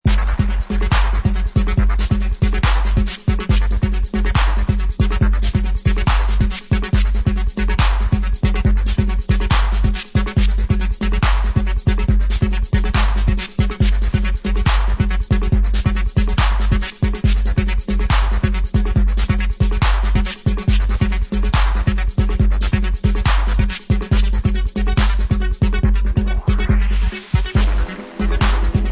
UK dancehall reggae, jungle and hip-hop